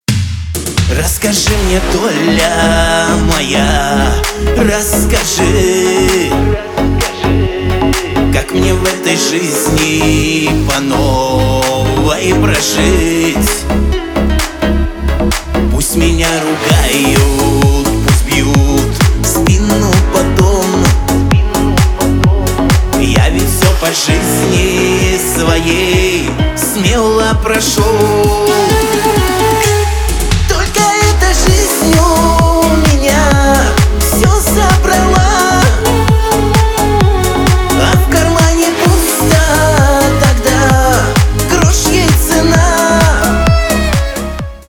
грустные
шансон